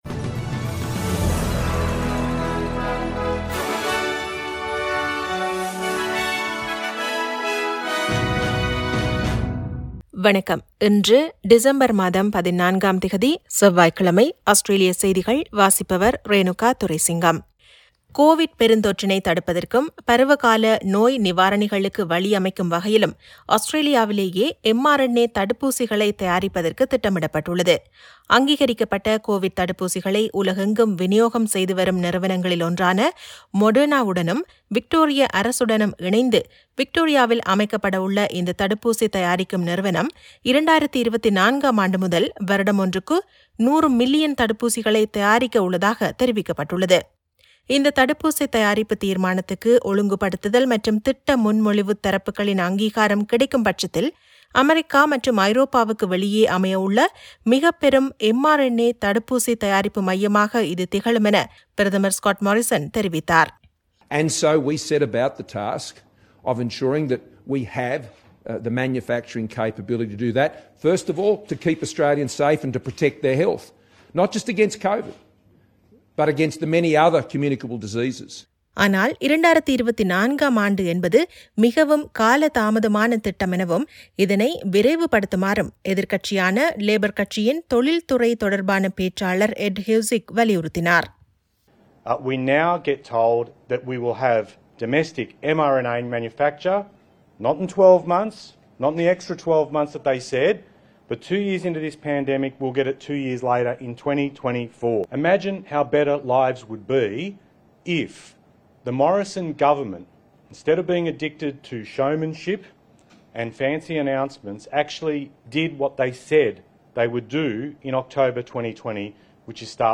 Australian news bulletin for Tuesday 14 December 2021.